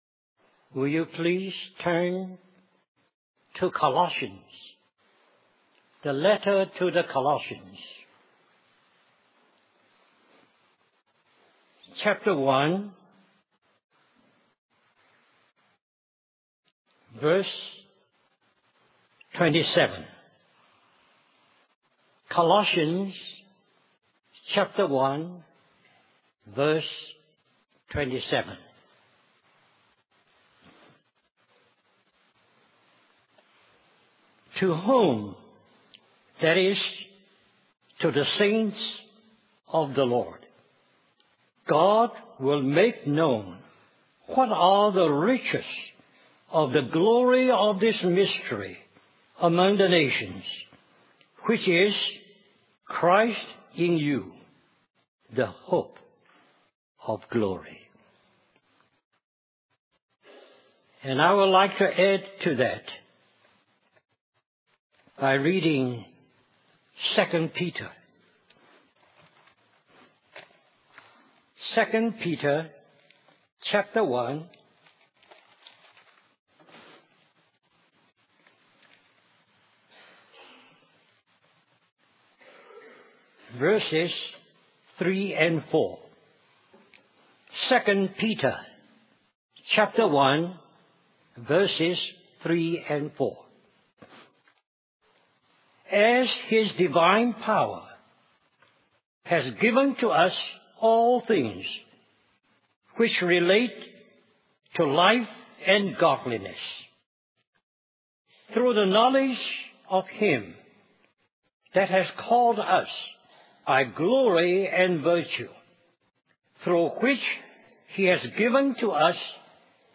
A collection of Christ focused messages published by the Christian Testimony Ministry in Richmond, VA.
2004 Christian Family Conference Stream or download mp3 Summary How do we go about fulfilling these responsibilities that the Lord has given to us. How do we go about prepared for His return, honoring Him, and using the gifts that He has given?